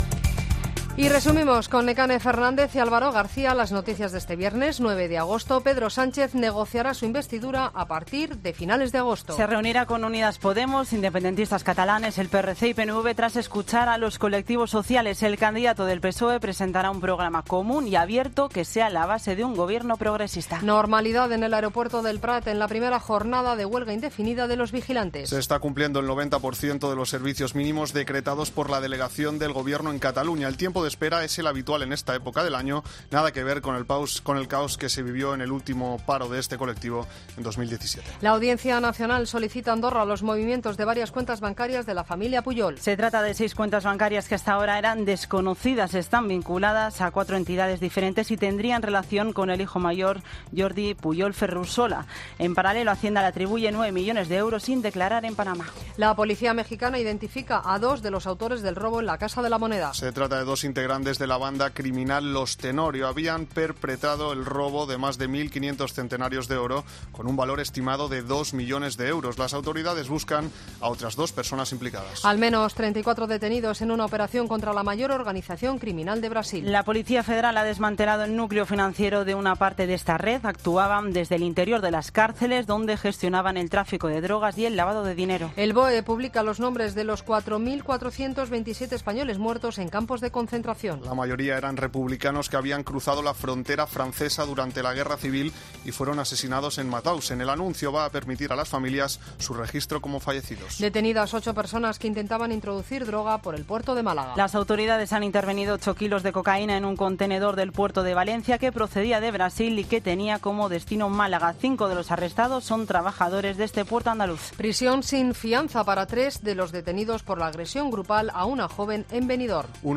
Boletín de noticias de COPE del 9 de agosto de 2019 a las 20.00 horas